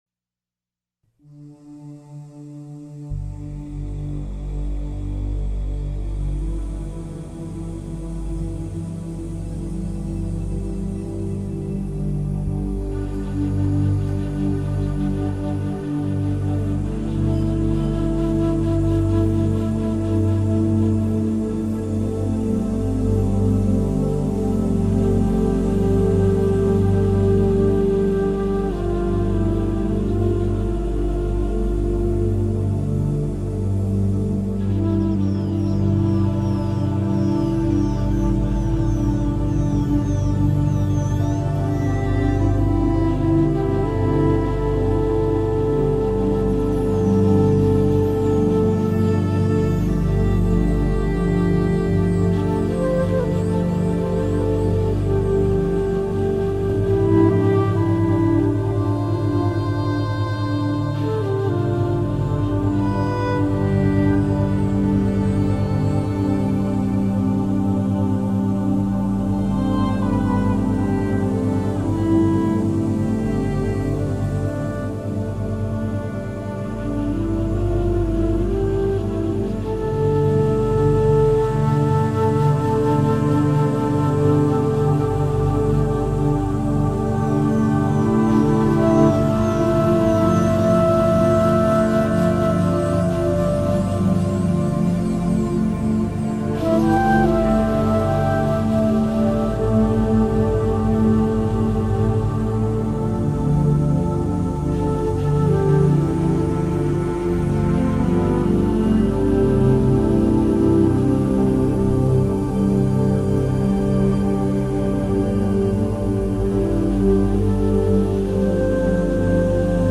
Frecuencias sanadoras para fortalecer tu sistema inmunológico (10 minutos diarios) Respira, suelta y regresa a ti...
10-min-healing-waves-for-inmunne-system-boosting-YrDWKQ1rWZi9vO0Z.mp3